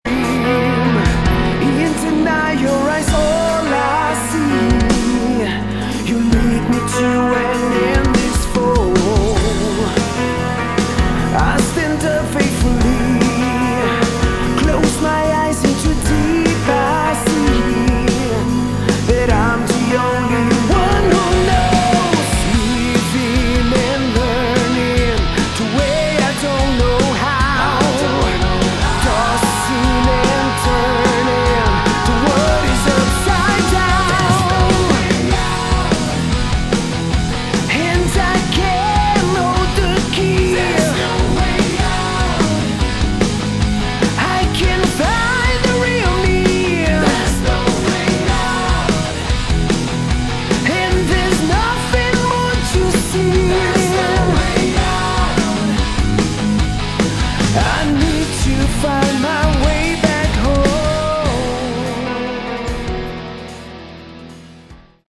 Category: Hard Rock
vocals
drums
guitars
bass